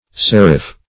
Seriph \Ser"iph\, n. (Type Founding)